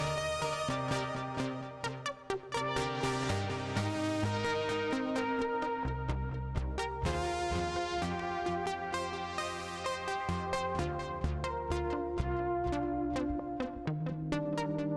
i dont feel like going through all my presets now but here’s six i just did. some are more similar just gritty fm brassy stuff, but i there is a nice quality to the synth where you can get high notes that are clean woody almost digital dx fm sounding but organic while the low notes and chords are fuzzy and warm at the same time. I’ll try to find some of those later, but these are just some i was able to record quickly just now. sorry about the volume differences, i forgot to normalize a couple. also excuse my playing, i suck